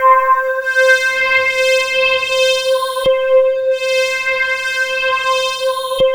Index of /90_sSampleCDs/USB Soundscan vol.13 - Ethereal Atmosphere [AKAI] 1CD/Partition E/11-QUARTZ